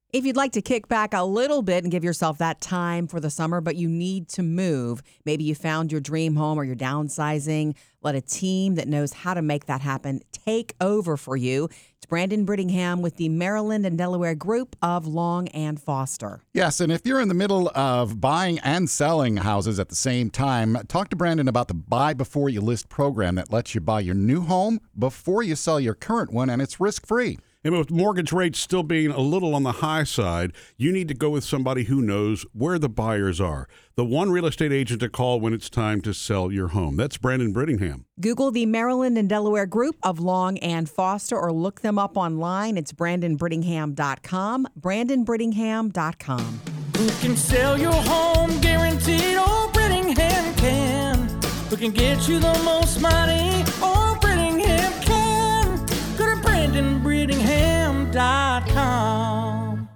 Celebrity Real Estate Agent Endorsements (Celebrity Cameos for Realtors) Celebrity Endorsement Agency | Radio & Television Experts